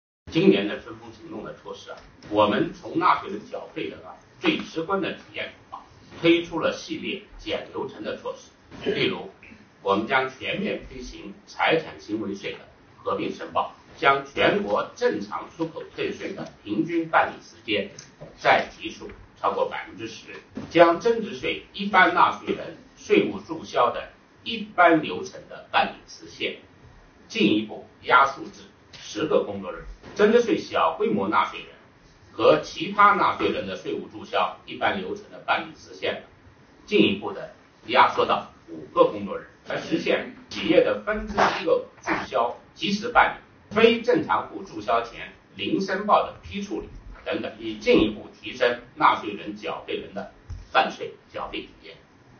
近日，国家税务总局举行新闻发布会，启动2021年“我为纳税人缴费人办实事暨便民办税春风行动”。国家税务总局纳税服务司司长韩国荣表示，今年的“春风行动”，税务部门将从纳税人缴费人最直观的体验出发推出系列简流程措施。